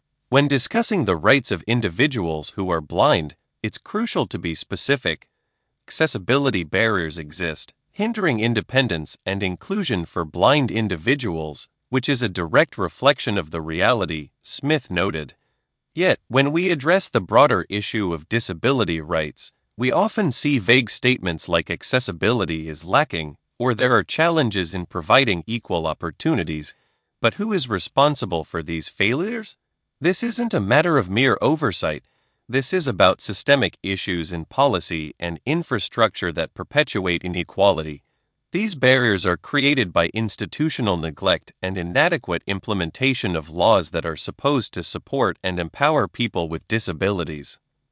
Glad to share with you this sample of the second iteration of OptiSpeech-TTS. In this iteration I fixed some issues related to punctuation pauses and pitch intonation. Needless to say that the model generates quality speech while being very fast and efficient.